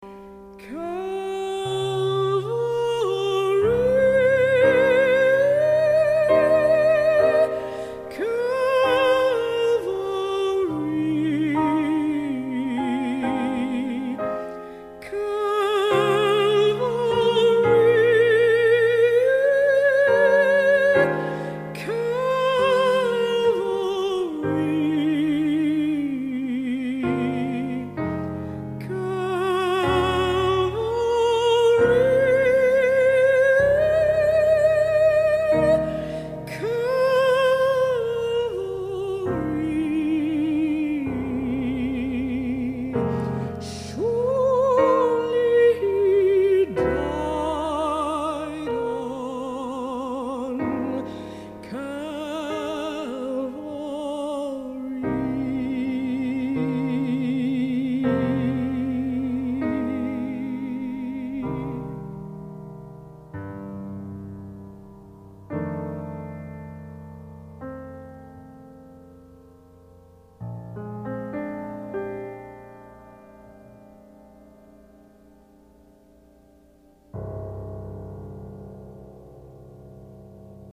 Voicing: Unison; Solo